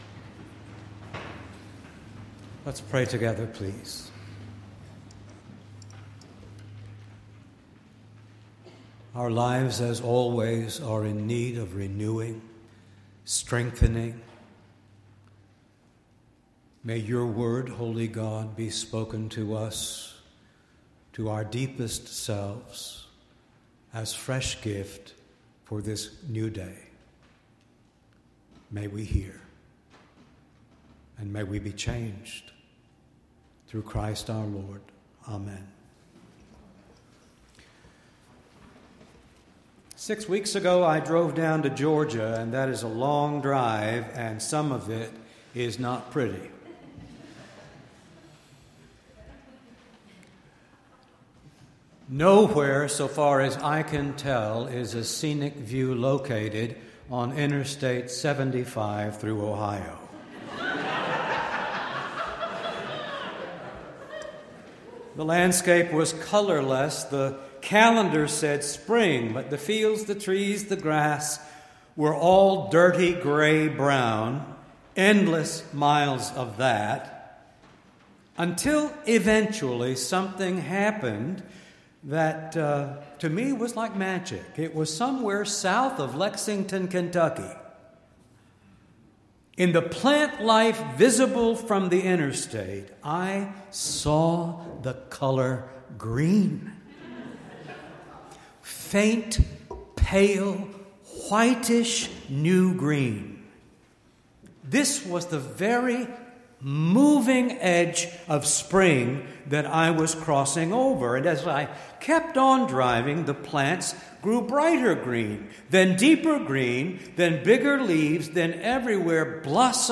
5-12-19-sermon.mp3